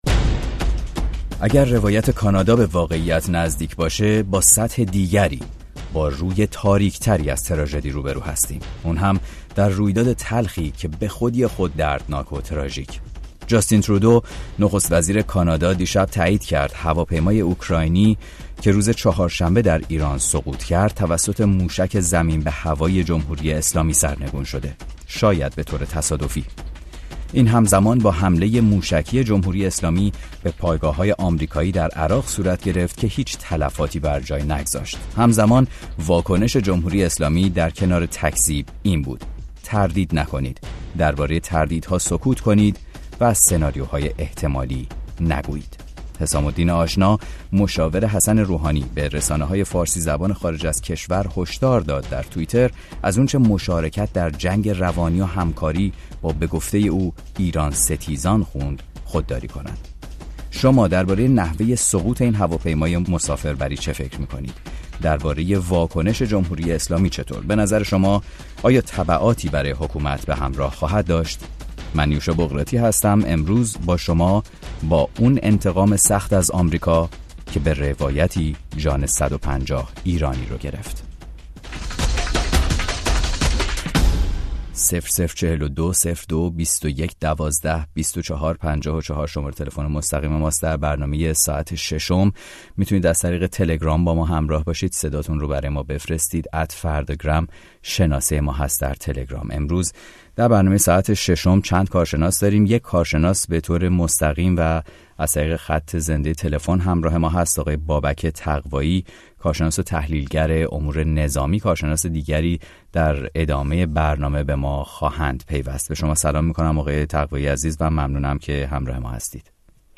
در این برنامه با کارشناسان مختلف و در همراهی با مخاطبان رادیوفردا به جوانب مختلف سقوط مرگبار پرواز شماره ۷۵۲ خطوط هوایی اوکراین می‌پردازیم.